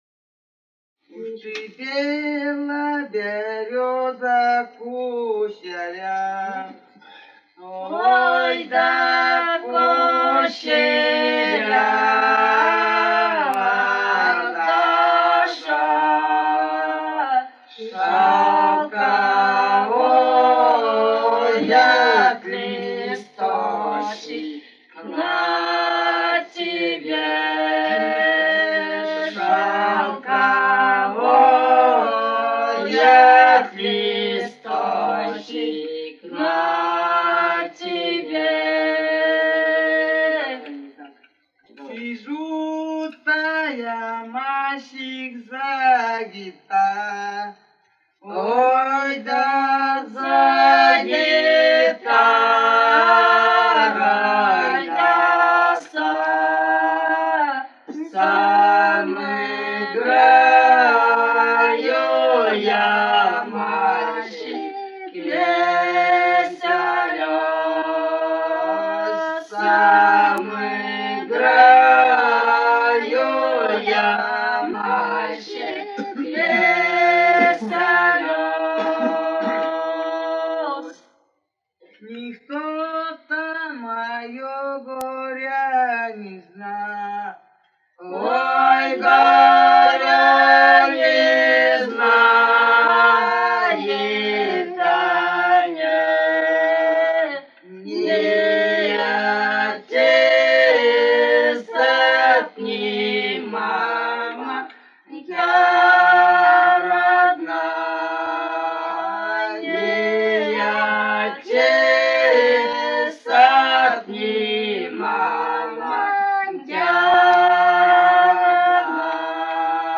с. Белое Катон-Карагайского р-на Восточно-Казахстанской обл.